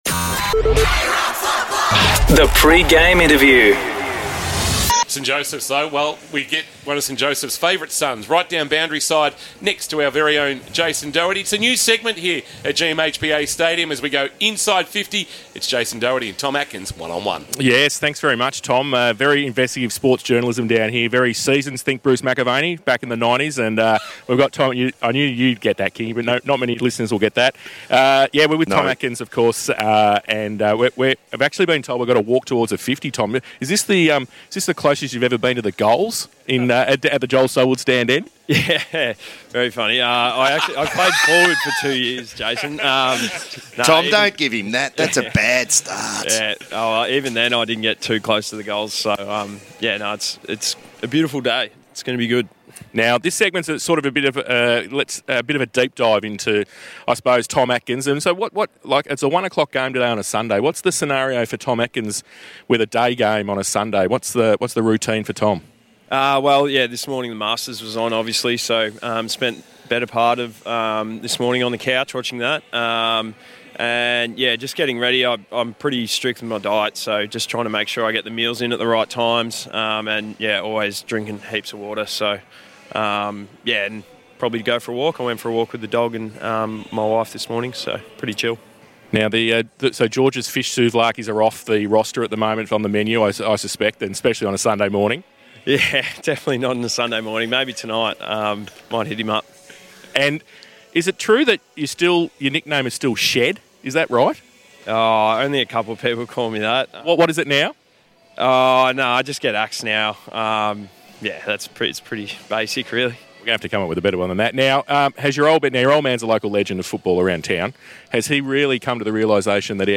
2024 - AFL - Round 5 - Geelong vs. North Melbourne: Pre-match interview - Tom Atkins (Geelong Cats)